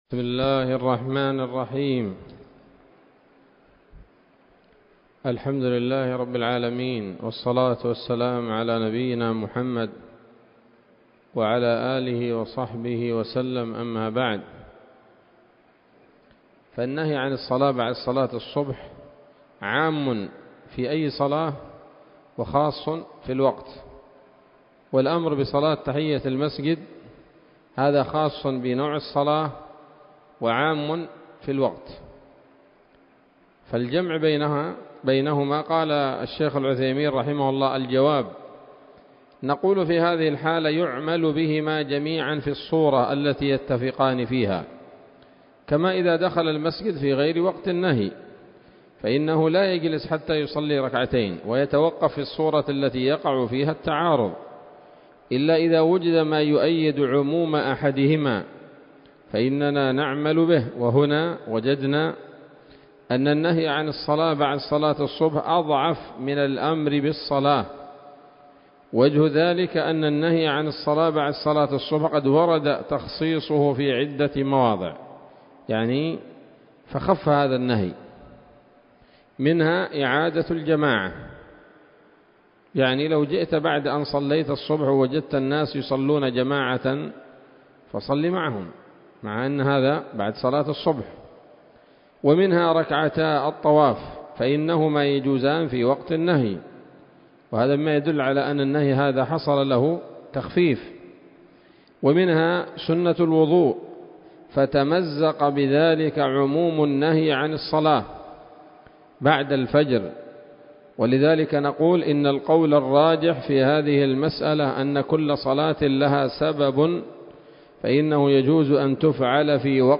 الدرس الثالث والخمسون من شرح نظم الورقات للعلامة العثيمين رحمه الله تعالى